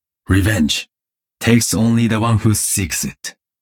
These voice lines are all from Heroes of the storm and I think they’re too good to not be in the game…plz jeff